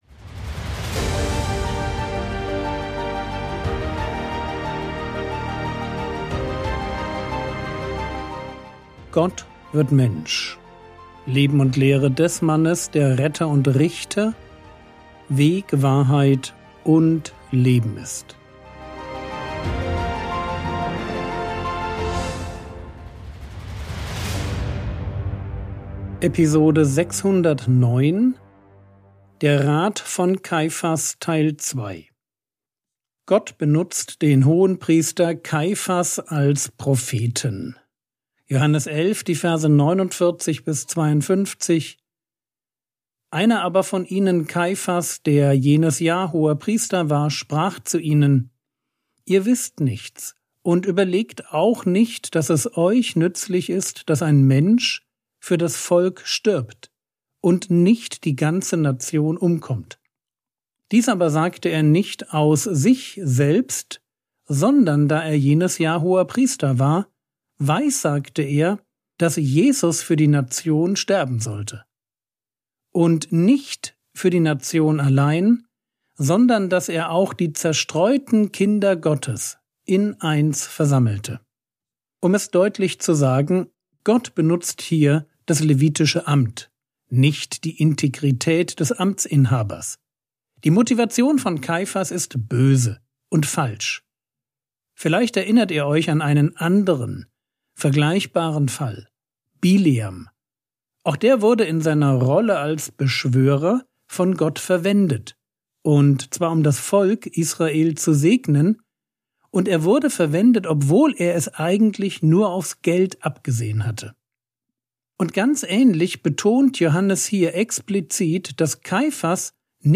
Episode 609 | Jesu Leben und Lehre ~ Frogwords Mini-Predigt Podcast